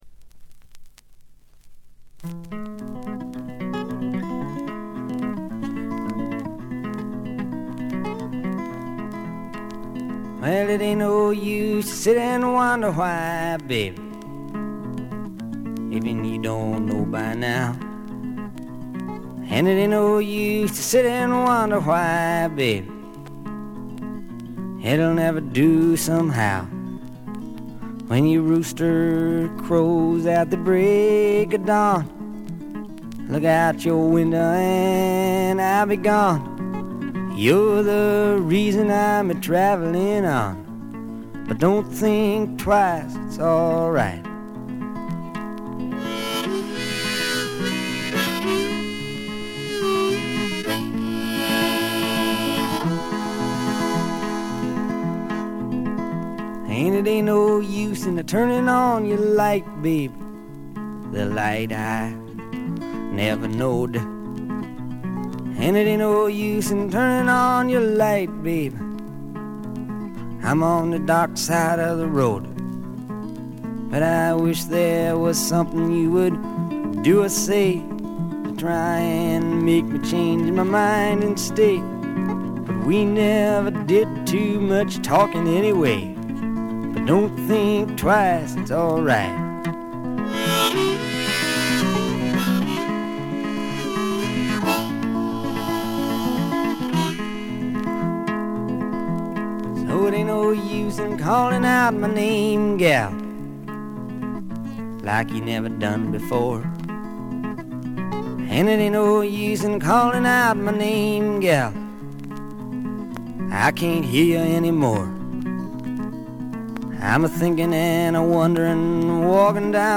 全体にバックグラウンドノイズ、チリプチ多め大きめですが音は見た目よりずっといい感じです。
試聴曲は現品からの取り込み音源です。